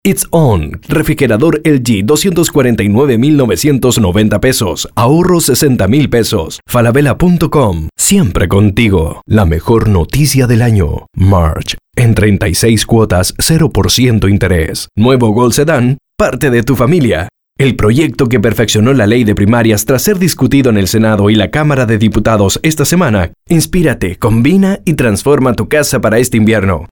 Masculino
Espanhol - América Latina Neutro